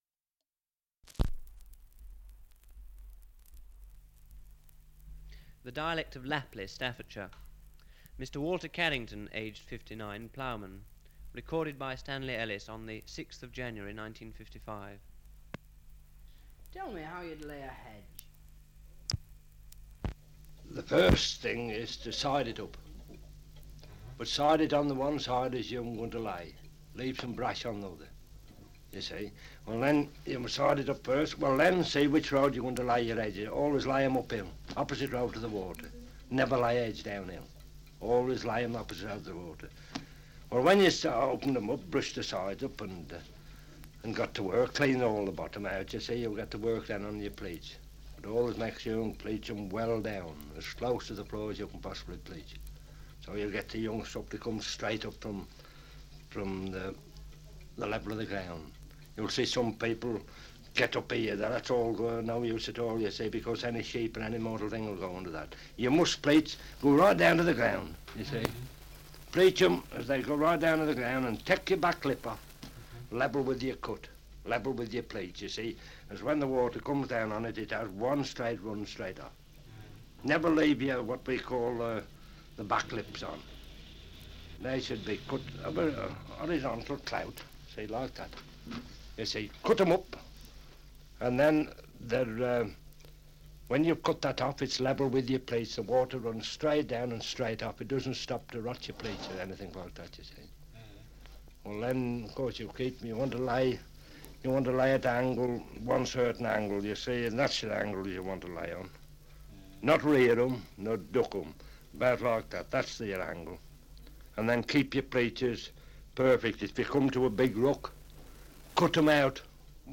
Survey of English Dialects recording in Lapley, Staffordshire
78 r.p.m., cellulose nitrate on aluminium